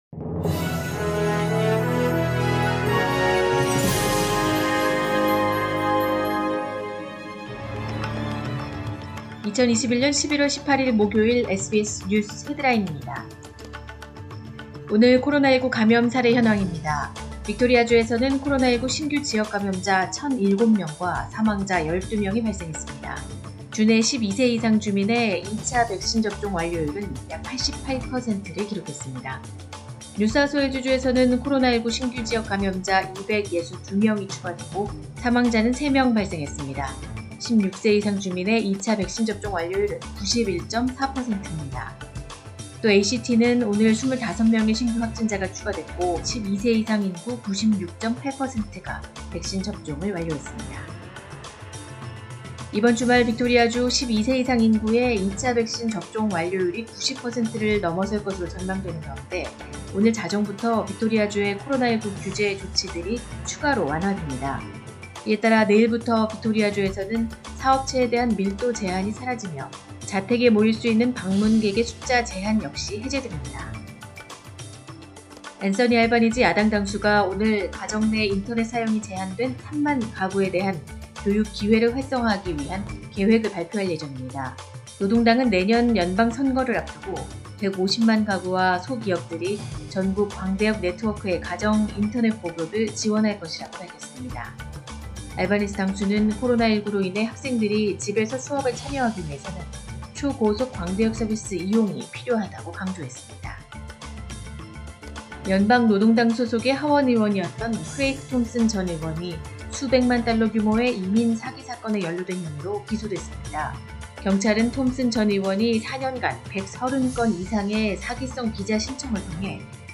2021년 11월 18일 목요일 오전의 SBS 뉴스 헤드라인입니다.